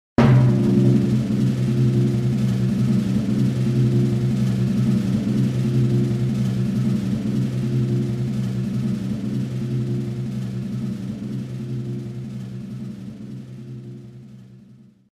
The drum roll sound that plays when pulling on the pipe